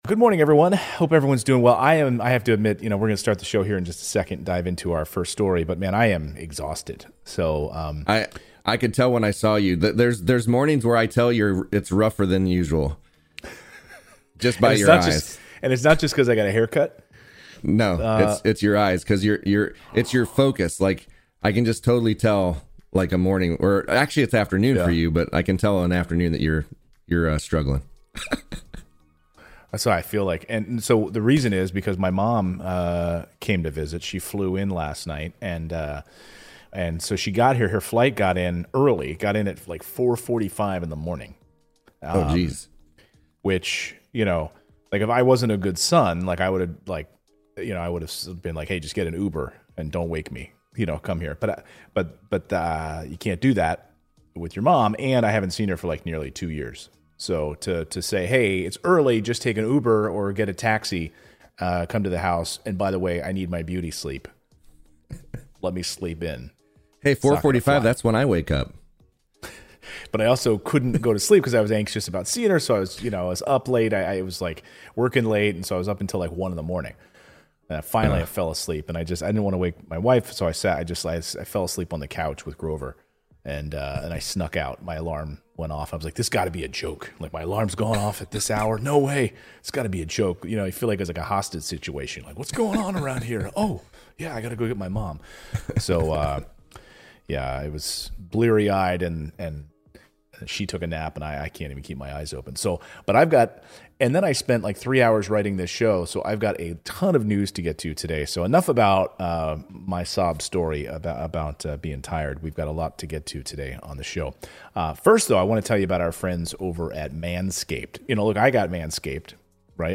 Progressives are renewing their call for additional stimulus to help. Meanwhile, corporate profits are breaking records but they're not raising salaries. Host Clayton Morris covers the day's news without the spin.